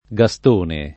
gaSt1ne] pers. m. — anche con g- minusc. come s. m. (col pl.